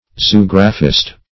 Zoographist \Zo*og"ra*phist\, n.
zoographist.mp3